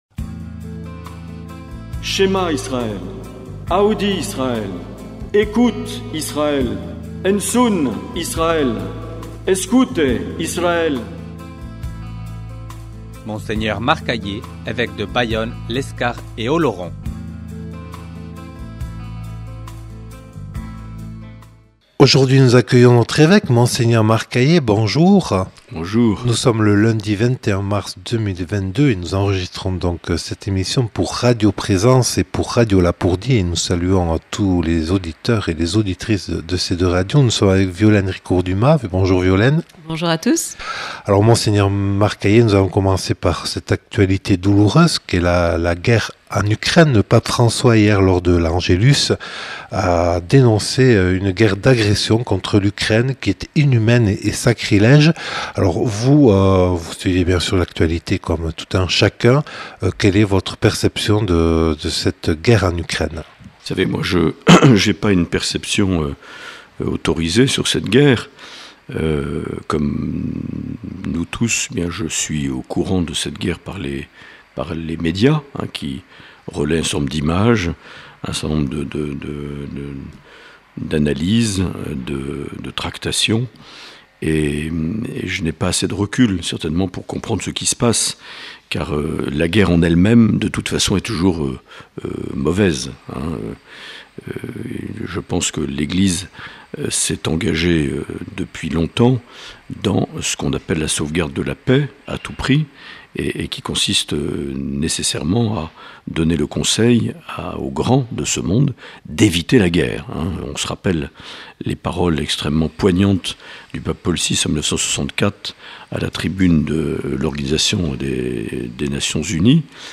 Entretien enregistré le 21 mars 2022. Sujets abordés : la guerre en Ukraine ; les élections à venir ; les finances et les projets immobiliers du diocèse ; les évènements de la vie diocésaine.